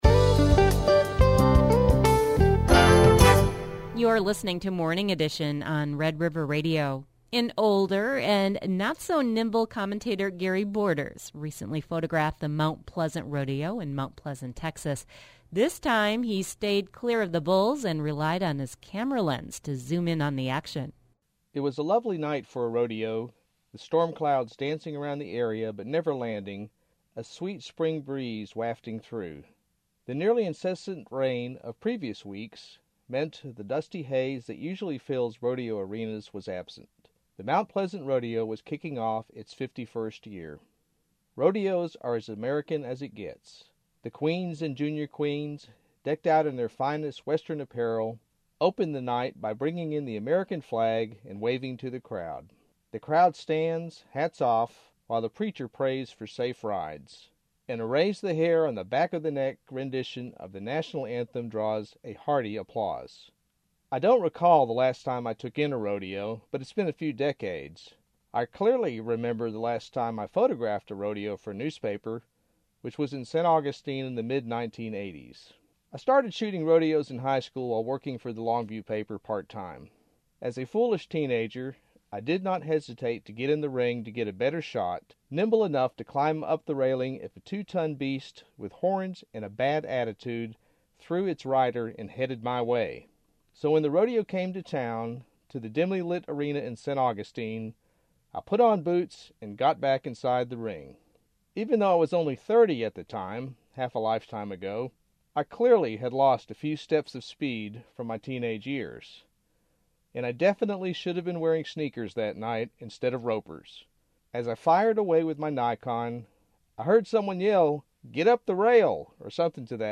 Shooting the rodeo commentary